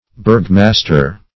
Bergmaster \Berg"mas`ter\, n.